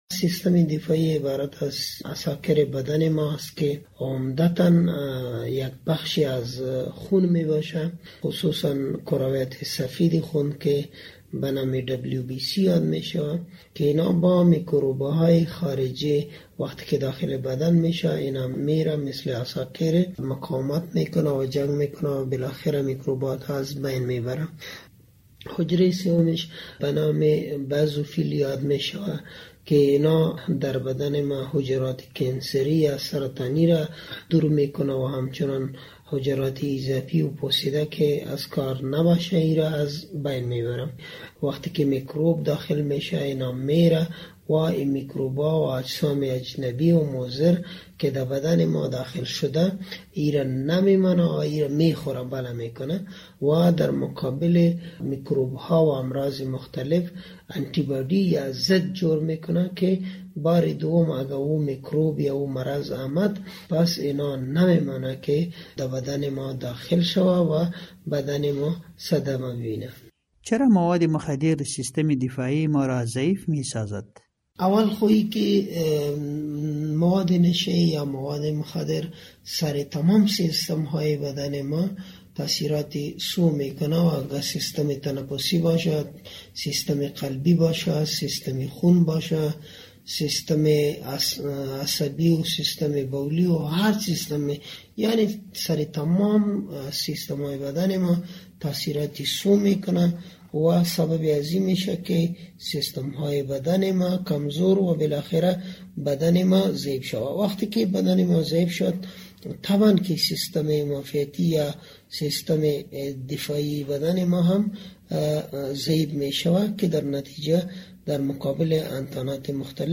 همکار ما در مصاحبه